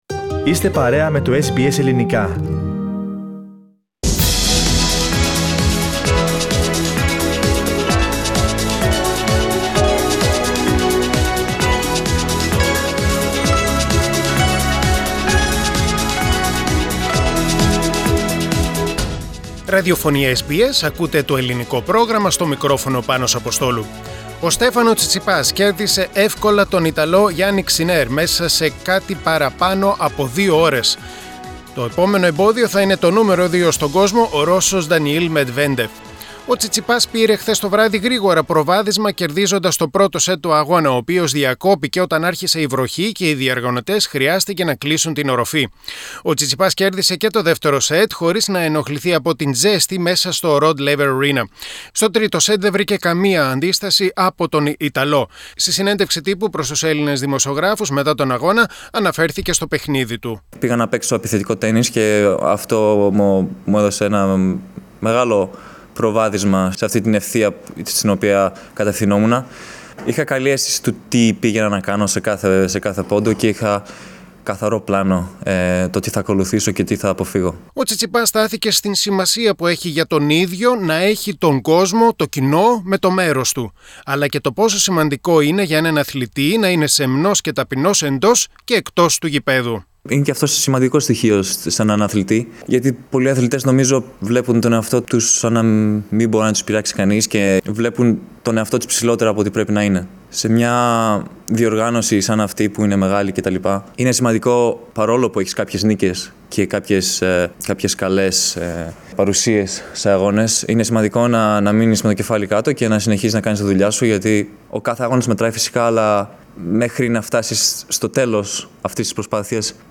Ο Στέφανος Τσιτσιπάς επικράτησε με 3-0 σετ (6-3, 6-4, 6-2) του Γιαννίκ Σίνερ και πήρε την πρόκριση για τα ημιτελικά του Australian Open. Στην συνέντευξη Τύπου μίλησε για πολλά.